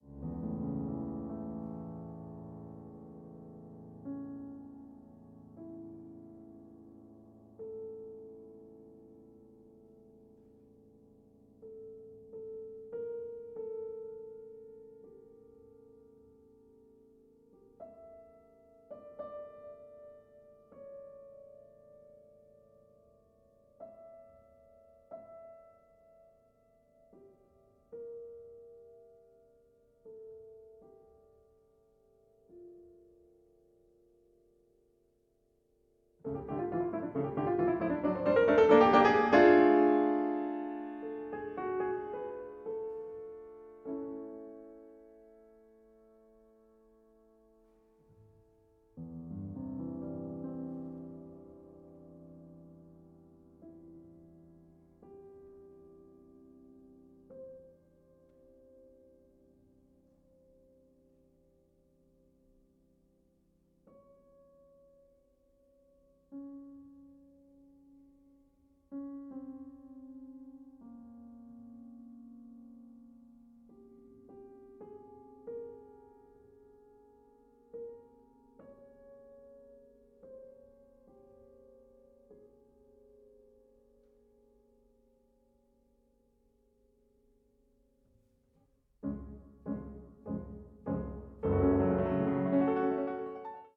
The main theme is perfectly sculpted from two different stones, such that we feel the conflict — the desperate pleas — at the work's very heart.
PIANO MUSIC